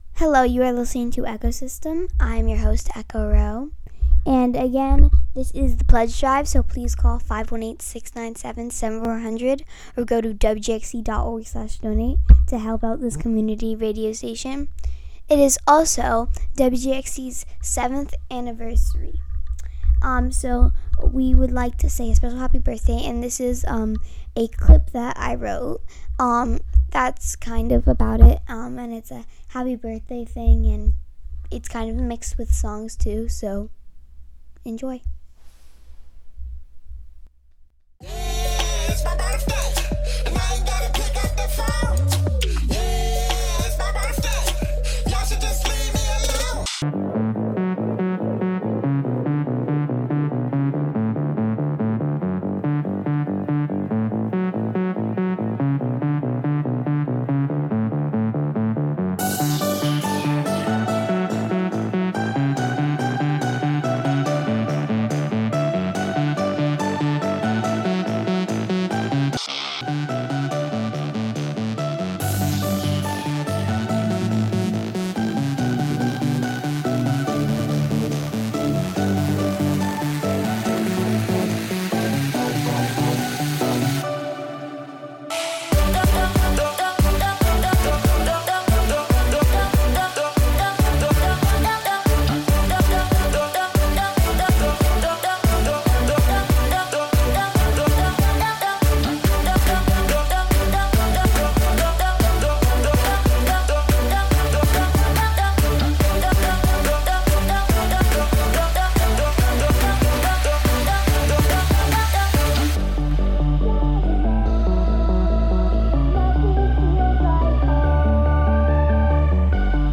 EchoSystem is a weekly youth program where friends come together to talk, learn, and experiment with sound, music, and radio.